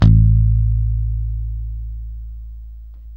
Index of /90_sSampleCDs/Roland - Rhythm Section/BS _E.Bass 2/BS _Rock Bass